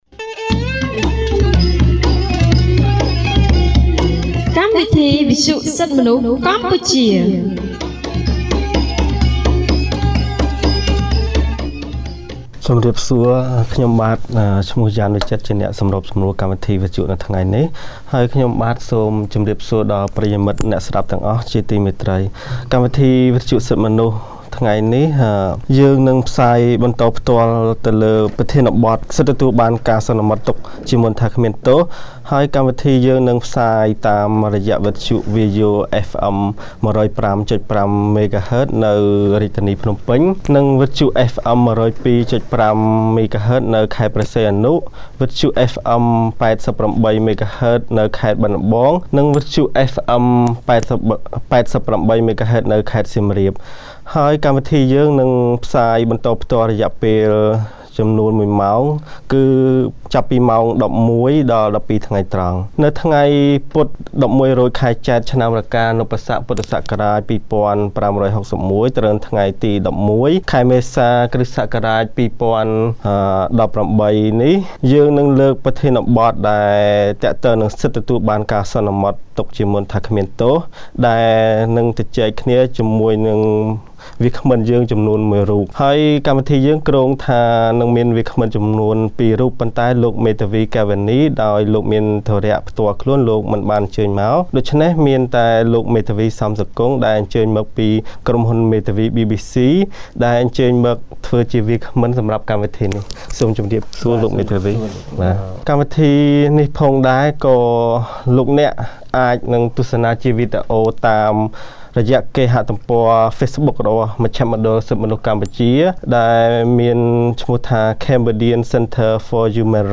On 11 April 2018, CCHR’s Fair Trial Rights Project (FTRP) held a radio program with a topic on Right to the Presumption of Innocence.